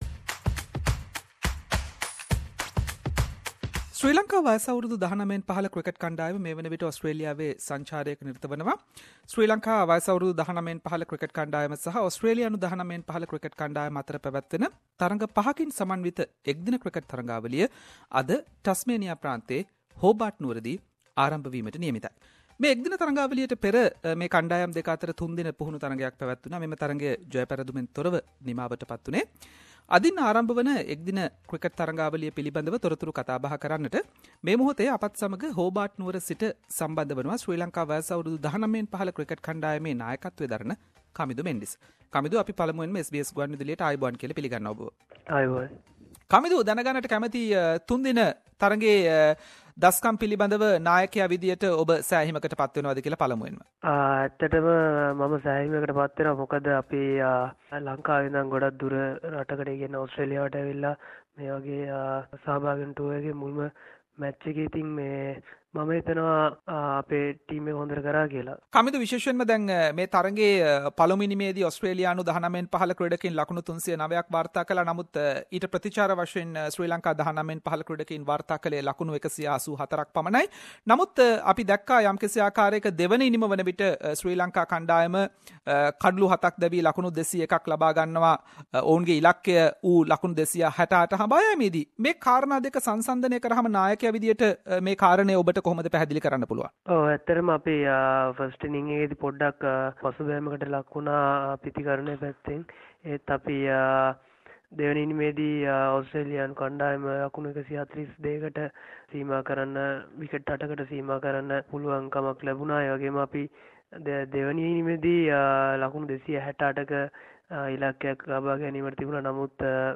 Sri Lanka Vs Australia u-19 ODI series begins today (April 13th) at Horbart and SBS Sinhalese interviewed Sri Lanka U-19 Cricket captain Kamindu Mendis regarding their Australian tour.